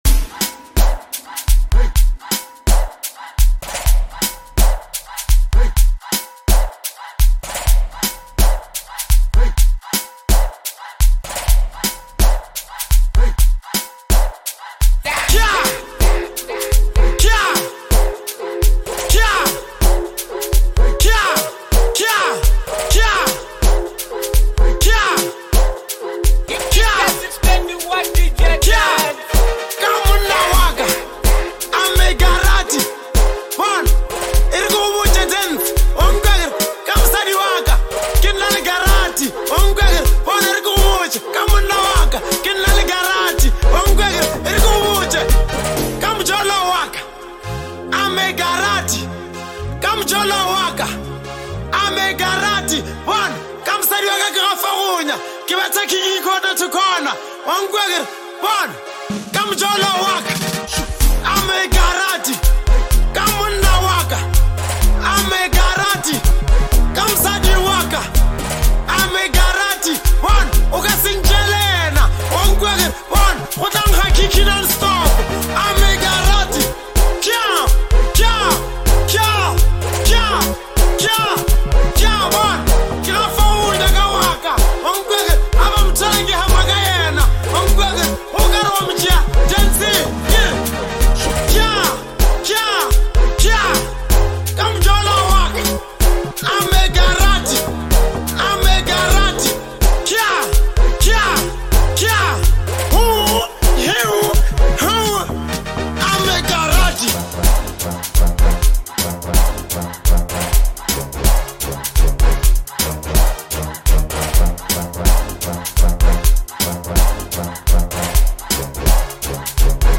is a high energy lekompo track